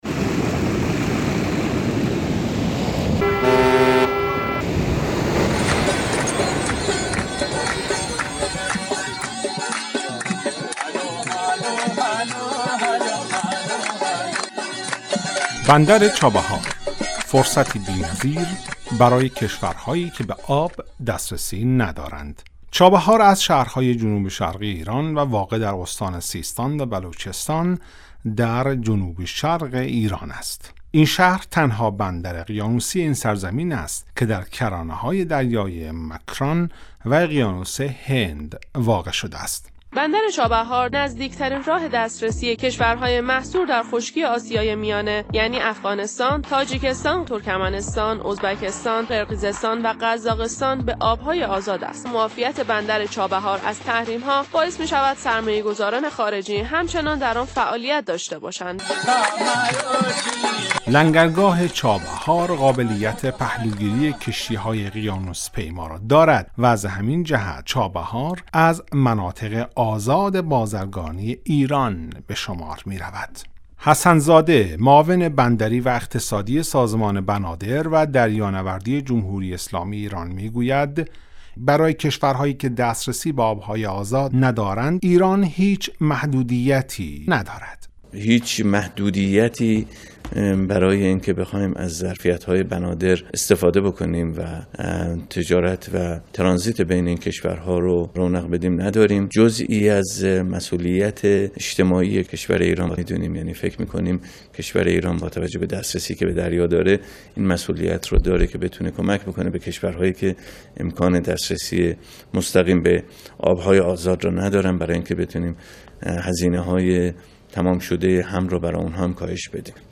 Гузориши ҳамкорамон